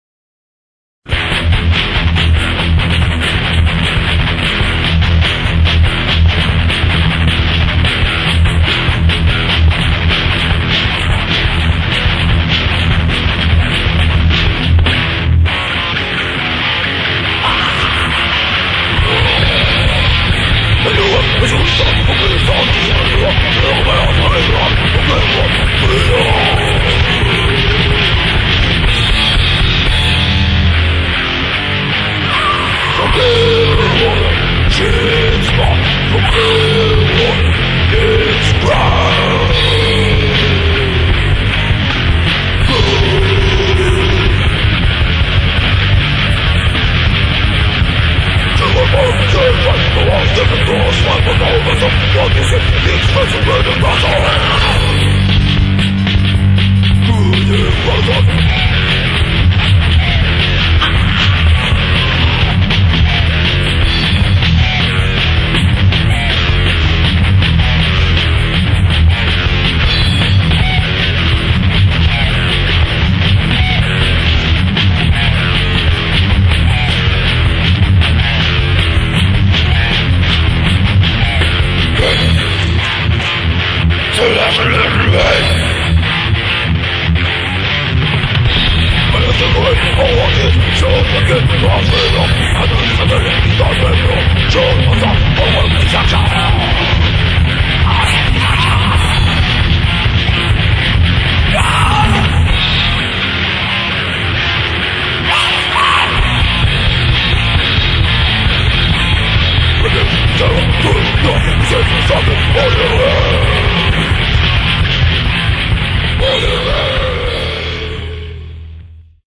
...всё это Rock"n"Roll......и не только...
Демоальбом - г. Актау